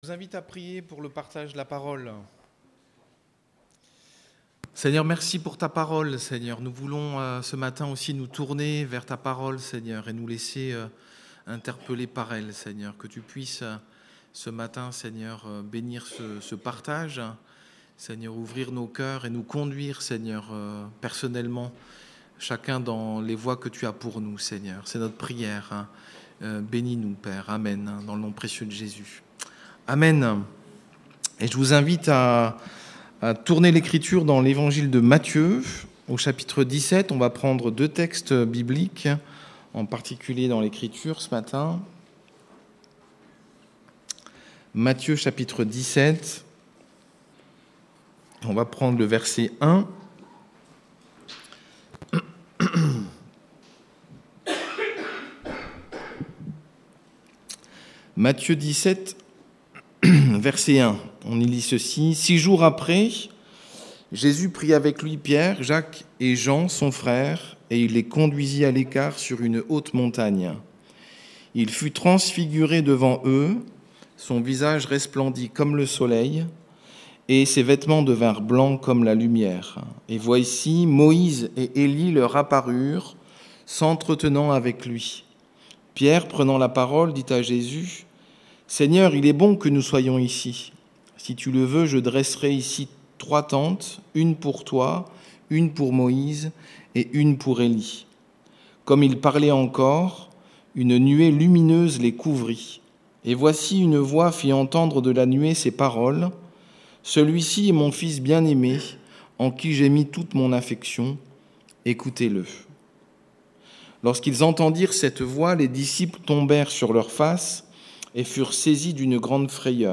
Prédications « Écoutez-le »